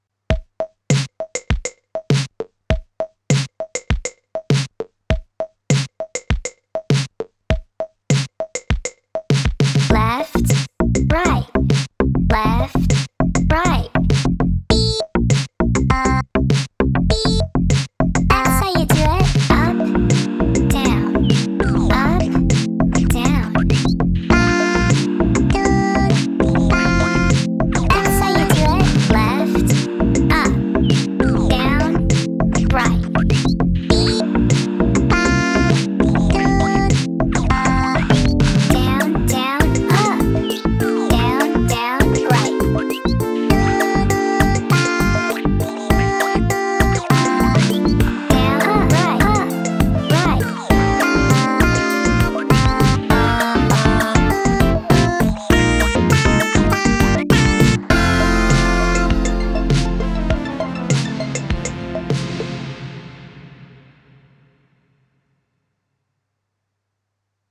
BPM100